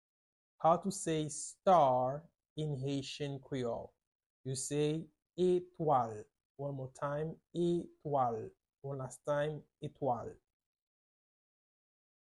Pronunciation:
Listen to and watch “etwal” audio pronunciation in Haitian Creole by a native Haitian  in the video below:
17.How-to-say-Star-in-Haitian-Creole-etwal-with-Pronunciation.mp3